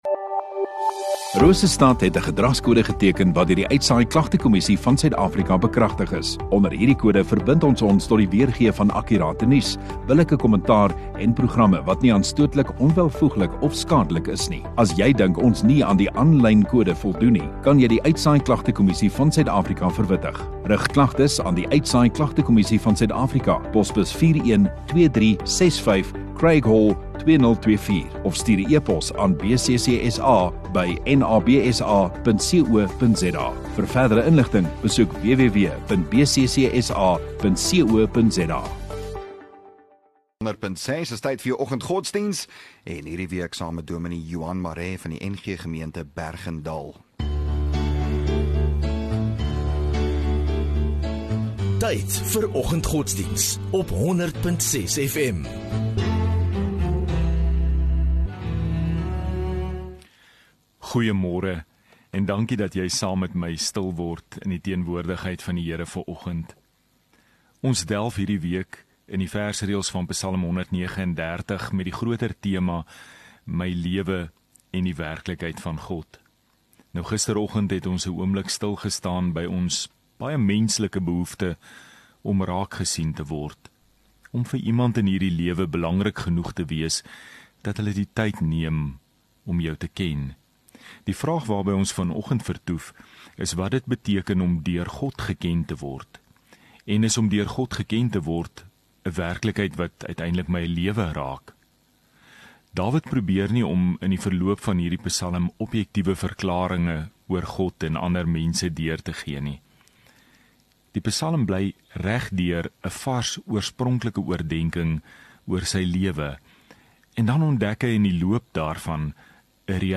2 Sep Dinsdag Oggenddiens